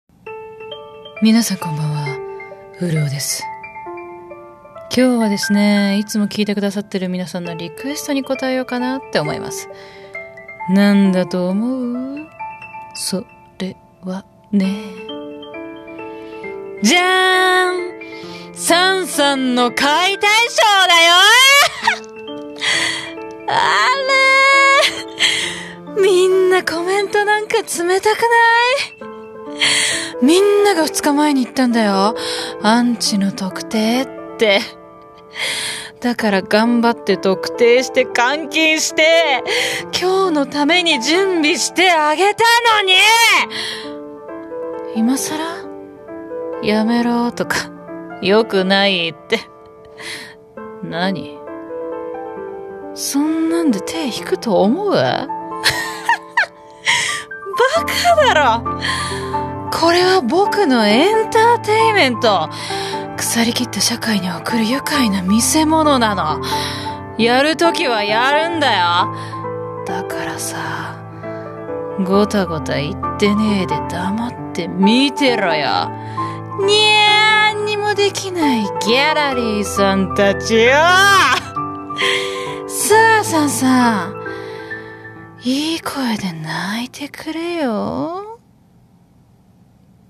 【一人声劇】配信